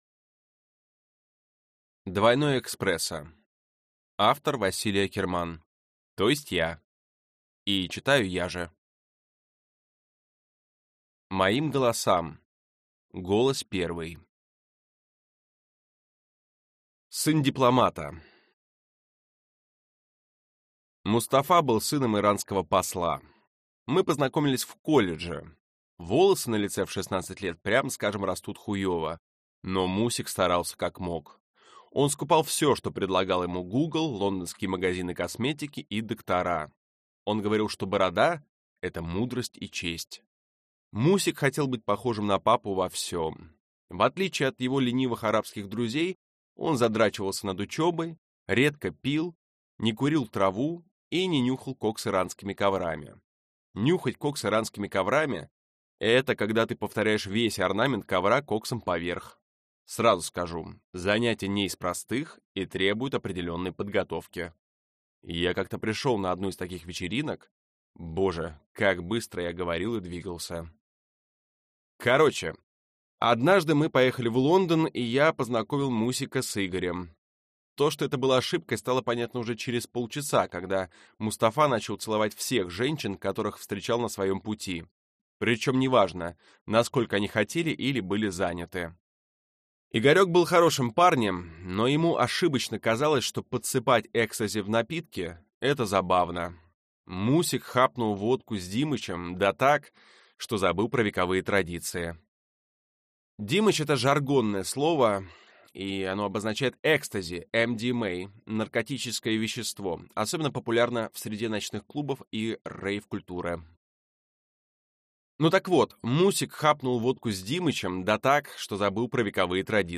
Аудиокнига Двойное экспресо | Библиотека аудиокниг